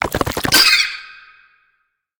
Sfx_creature_penguin_skweak_09.ogg